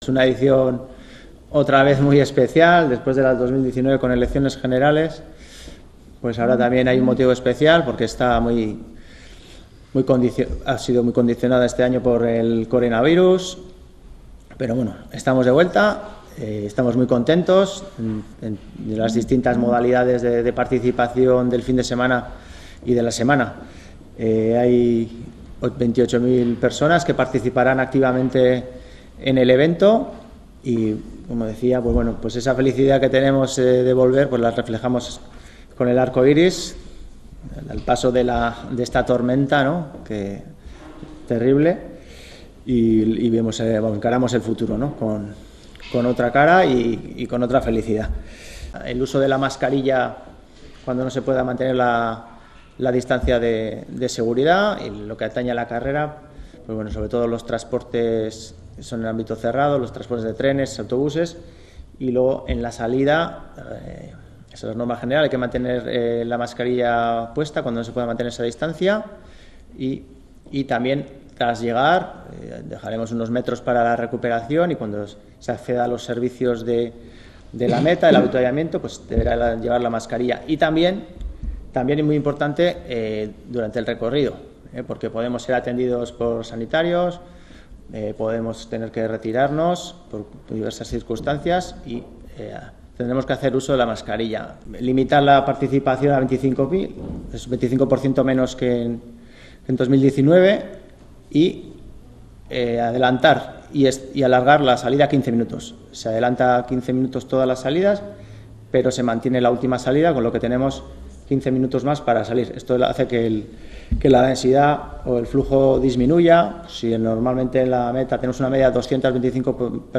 Los aplausos, la música y los ánimos volvieron a colmar cada palmo de recorrido, desde los primeros minutos con el paso de patinadores y atletas con discapacidad, hasta el último aliento de quienes cerraron la carrera. Puedes ver parte de ese ambiente al paso de la carrera por Pasai Antxo en el vídeo.